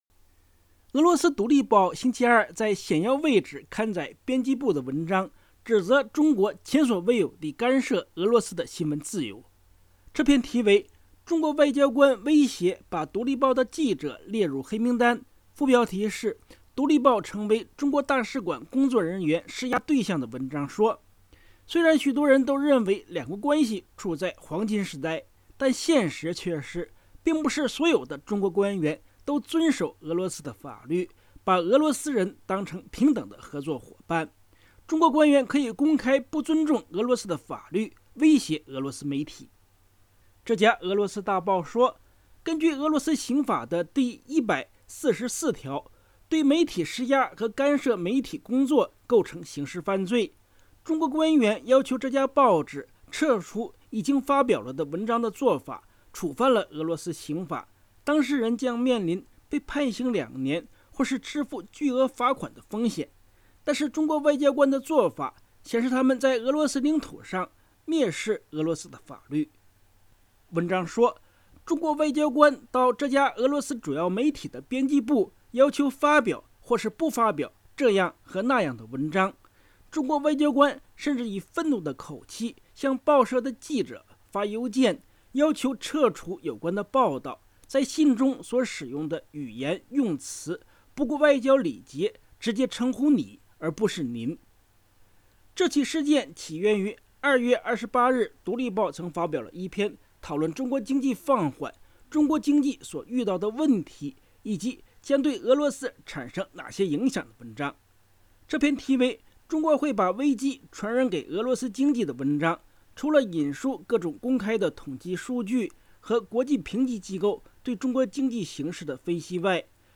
新闻及采访音频